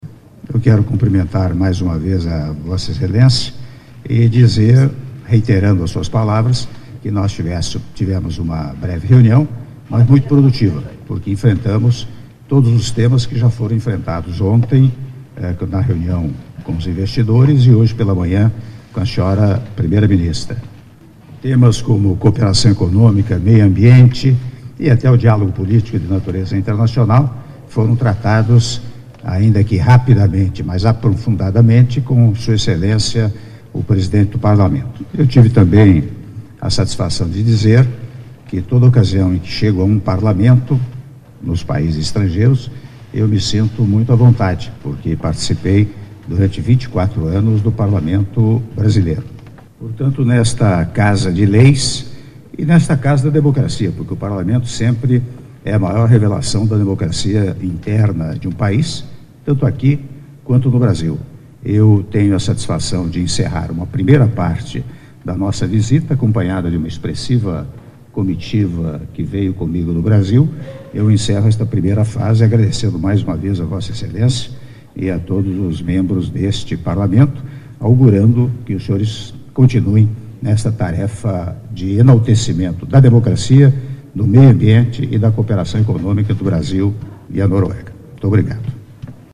Áudio da declaração à imprensa do Presidente da República, Michel Temer, após encontro com o presidente do Parlamento da Noruega, Olemic Thommessen - (01min29s) - Oslo/Noruega